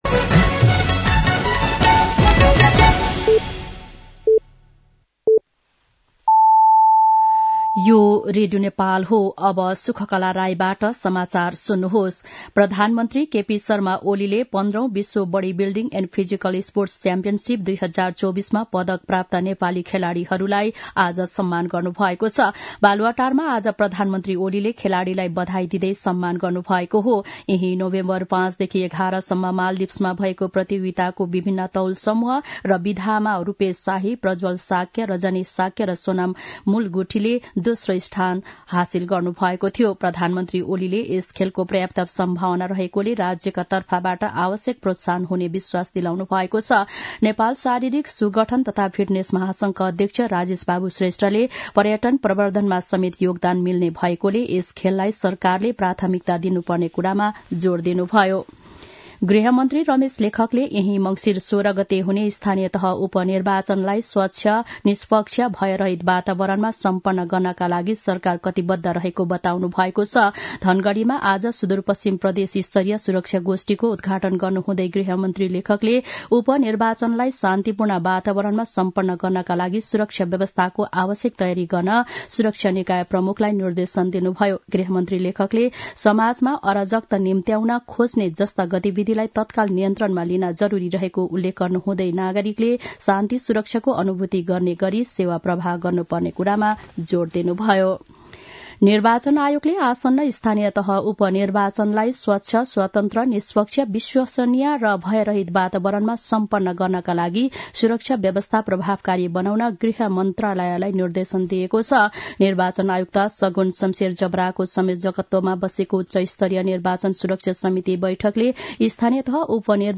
दिउँसो ४ बजेको नेपाली समाचार : ५ मंसिर , २०८१
4-pm-nepali-news-1-1.mp3